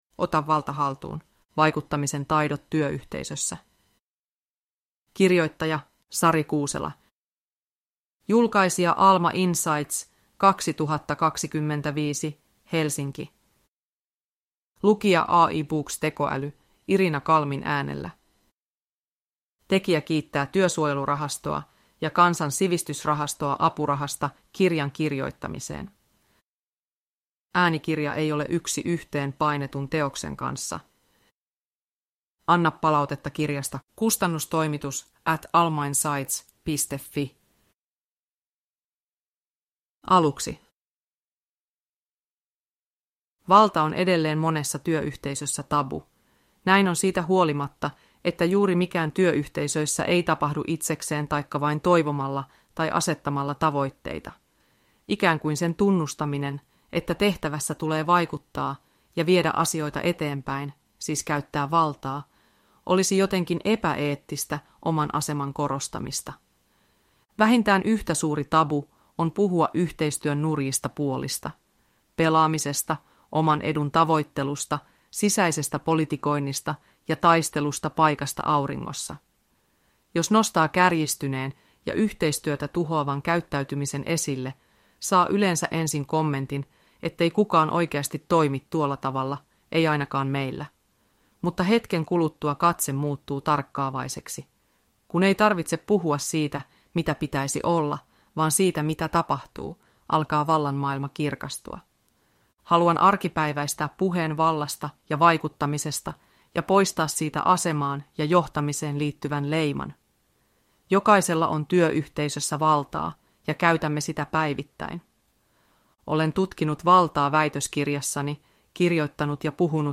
Ota valta haltuun (ljudbok) av Sari Kuusela